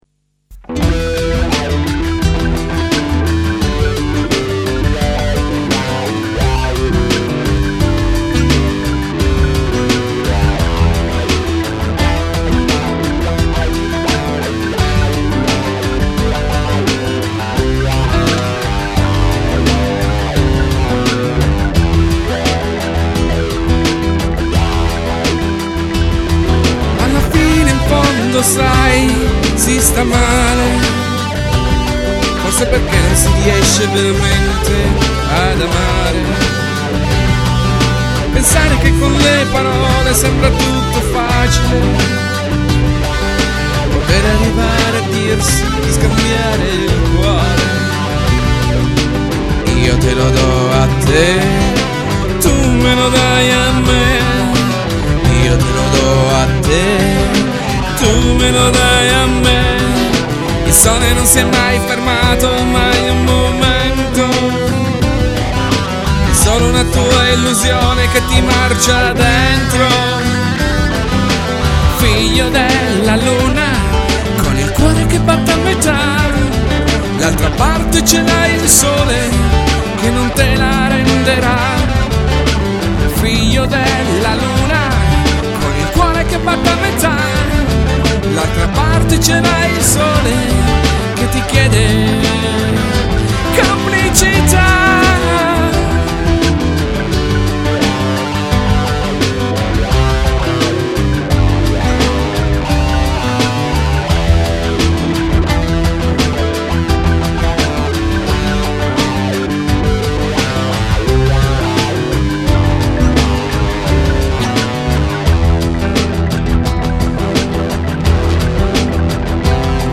MUSIC>rock
basso
batteria
voce, chitarre